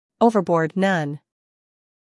音标
英音/ ˈəʊvəbɔːd / 美音/ ˈoʊvərbɔːrd /